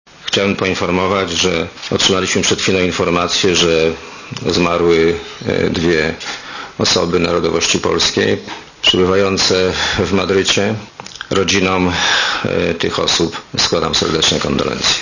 Komentarz audio
milleropolkach.mp3